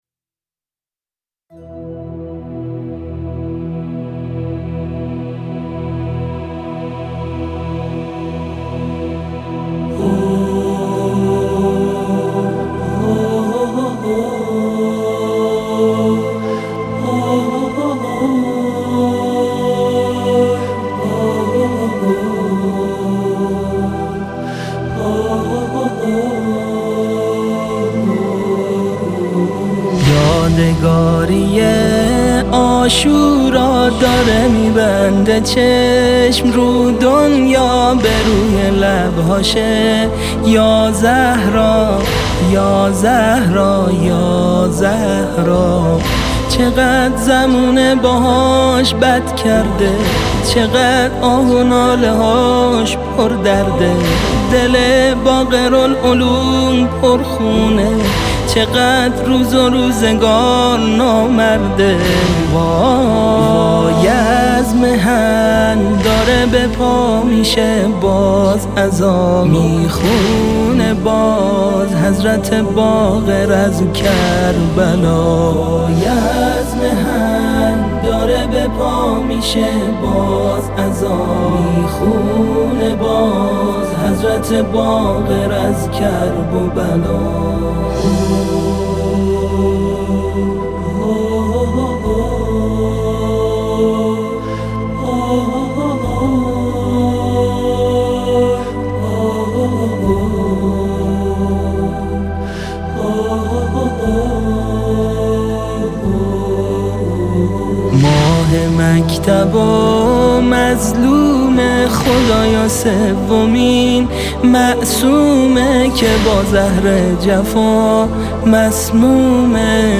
مداحی
نوای دلنشین